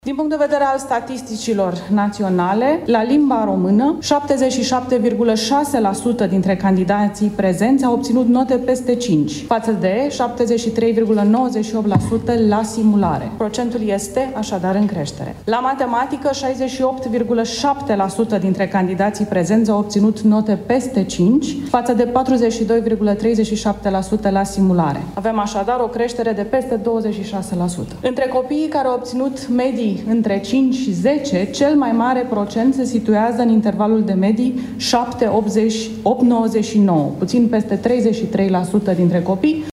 Ministrul Educației, Ligia Deca: „La limba română 77, 6% dintre candidații prezenți au obținut note peste 5 față de 73,93% la simulare”